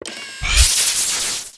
shocklance_activate.wav